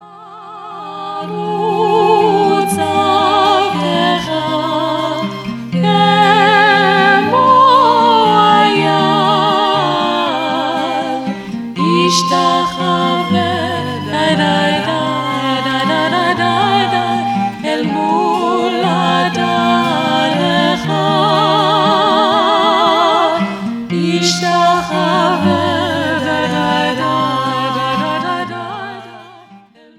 Beautiful melodies, powerful themes and perfect harmonies.